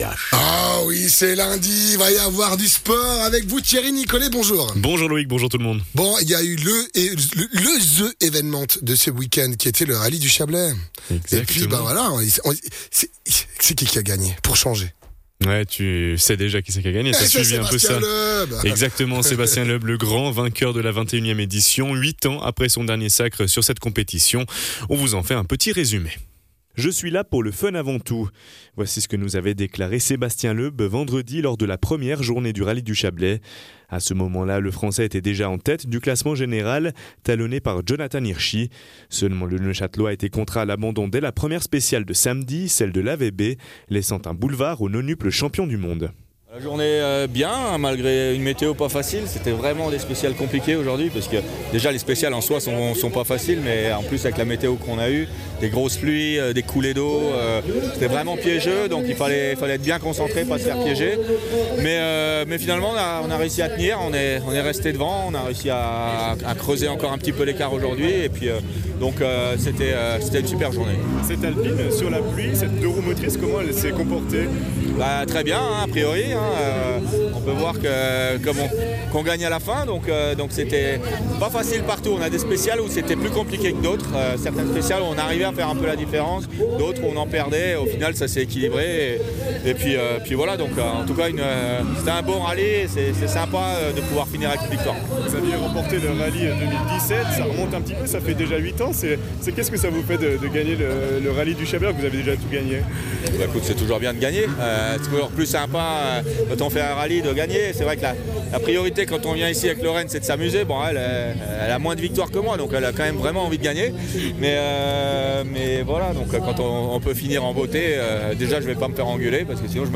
pilotes